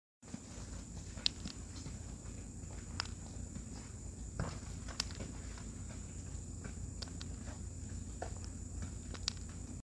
Eastern eyed click beetle.
They are 1 to 2 inches in length and 'click' their bodies to flip over. South Carolina's largest click beetle is not very common.